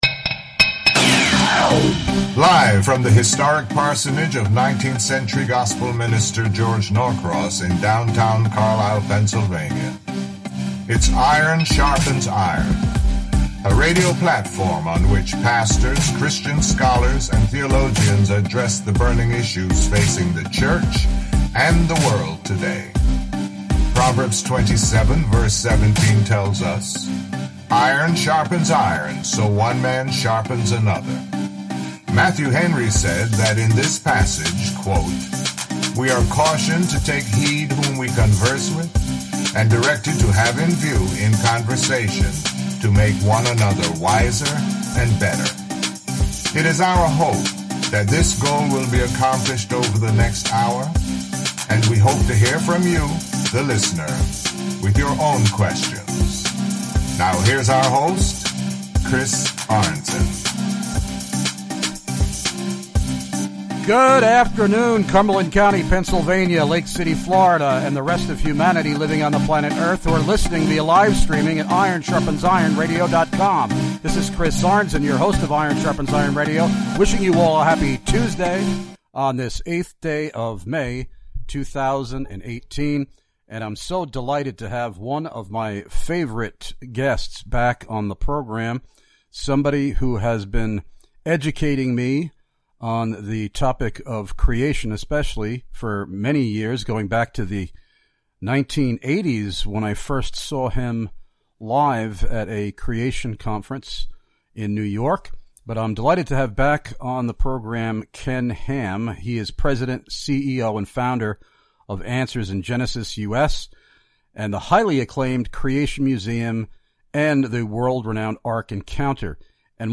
Guest #1: KEN HAM, President, CEO & founder of Answers in Genesis-US, & the highly acclaimed Creation Museum & the world- renowned Ark Encounter, & one of the most in-demand Christian speakers in North America, who will address: